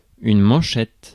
Ääntäminen
Synonyymit garde-manche Ääntäminen France: IPA: /mɑ̃.ʃɛt/ Haettu sana löytyi näillä lähdekielillä: ranska Käännös 1. ruedo {m} 2. puño {m} Suku: f .